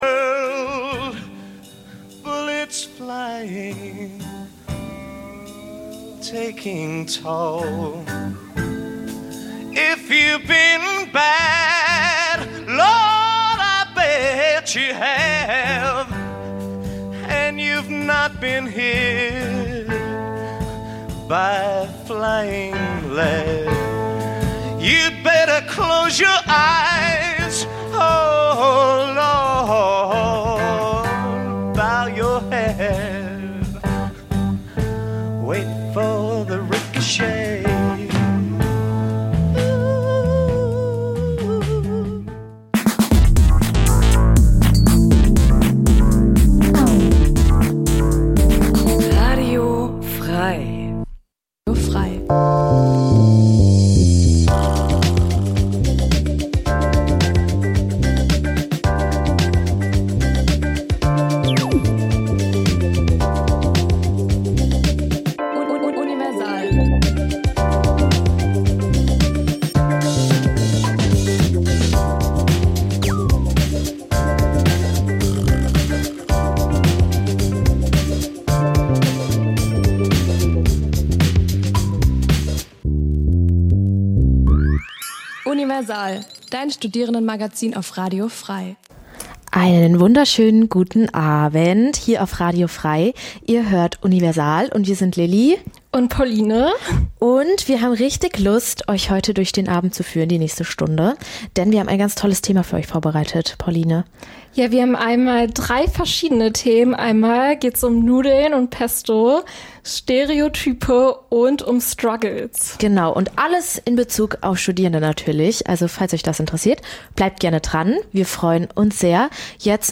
Die Sendungen werden gemeinsam vorbereitet - die Beitr�ge werden live im Studio pr�sentiert.